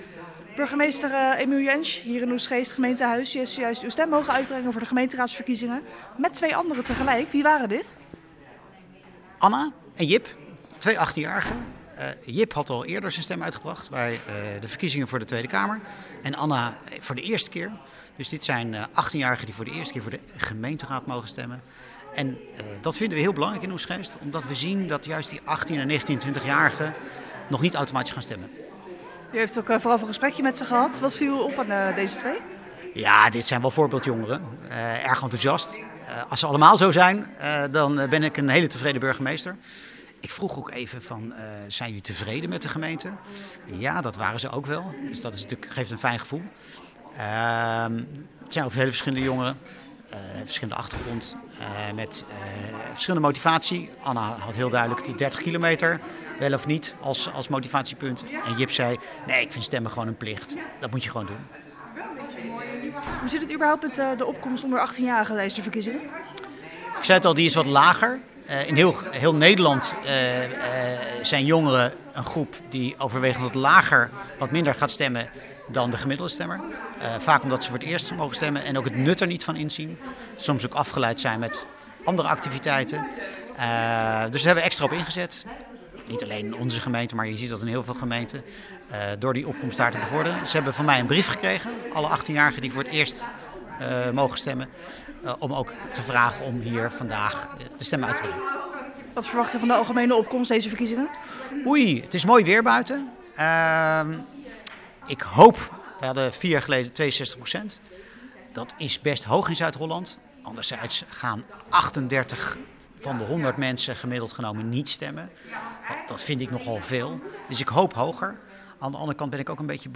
Verslaggever
in gesprek met burgemeester Emile Jaensch.